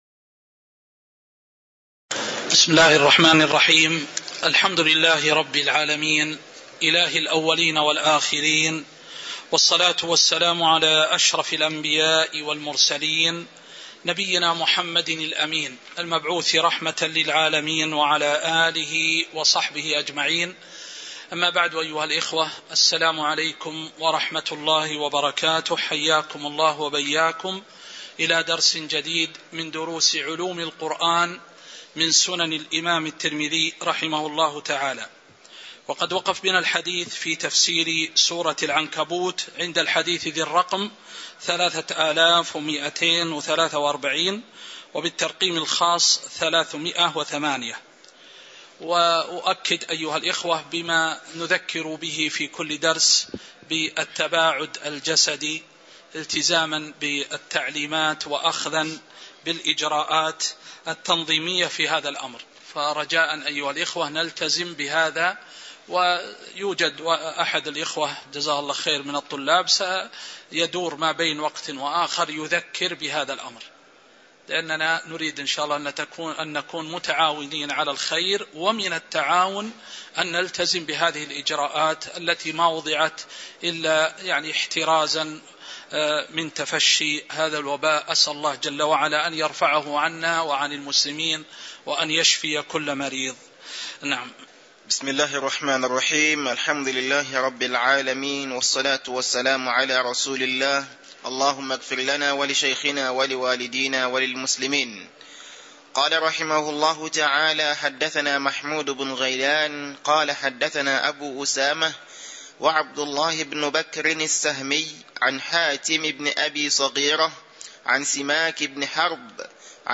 تاريخ النشر ١٣ جمادى الآخرة ١٤٤٣ هـ المكان: المسجد النبوي الشيخ